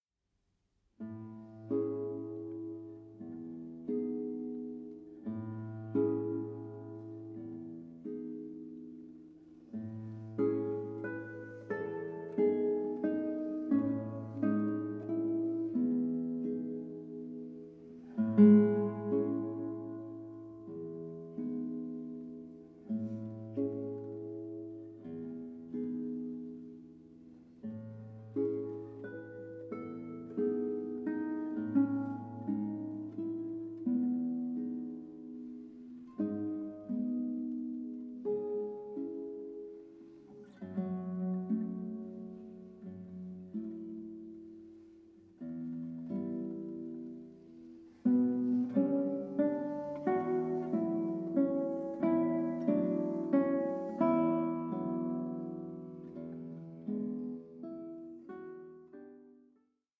INTROSPECTIVE EXPLORATION OF FRENCH-SPANISH MUSICAL BORDERS